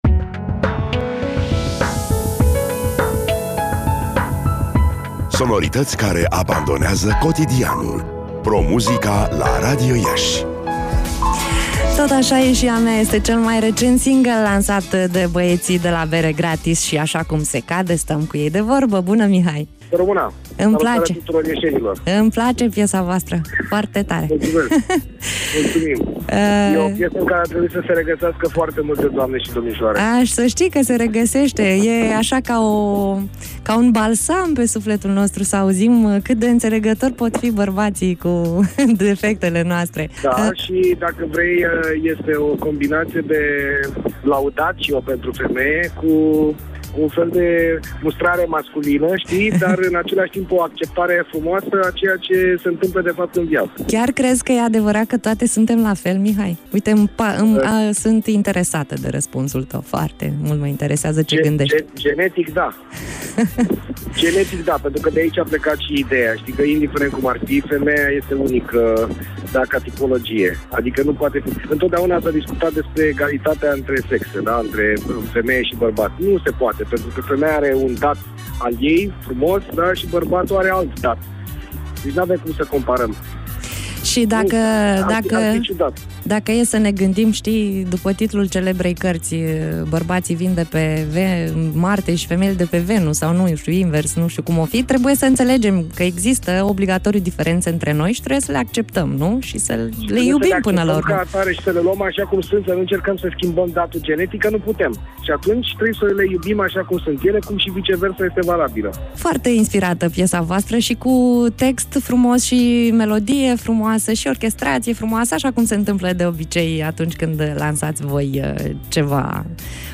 Interviu-PT-Site-Bere-Gratis.mp3